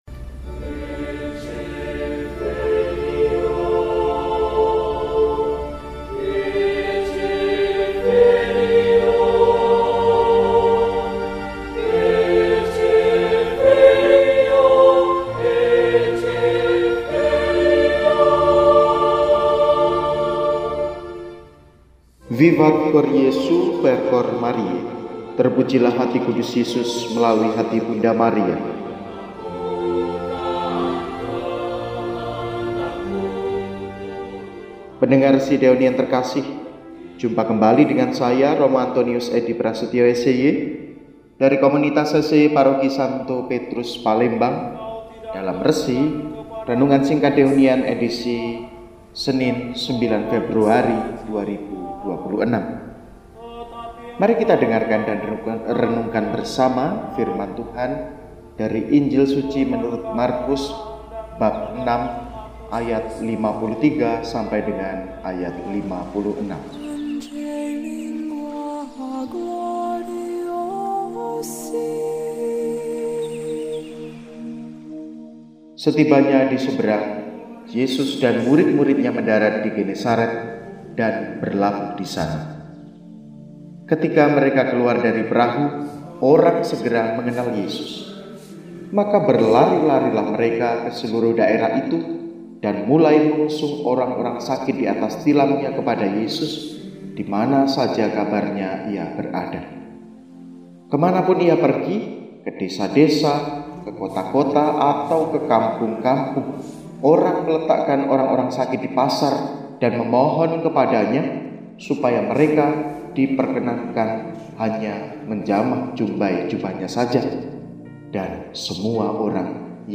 RESI DIBAWAKAN OLEH